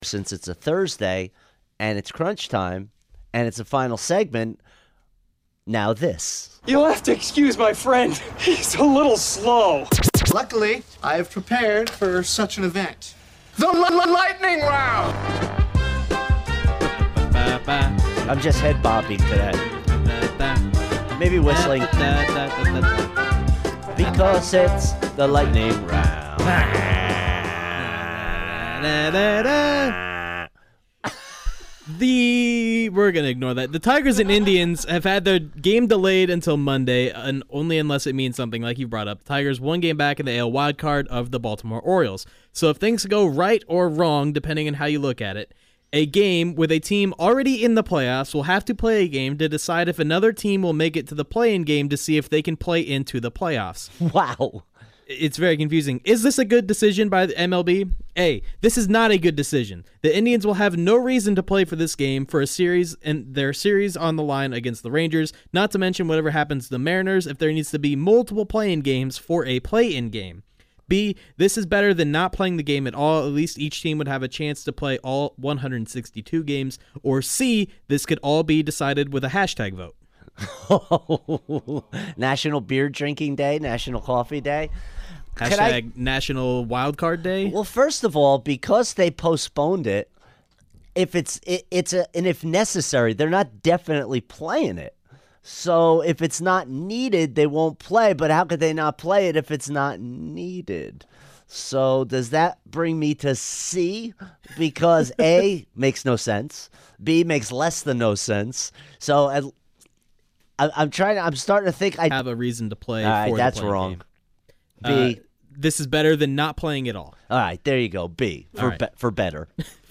fires through the days biggest stories rapid-fire style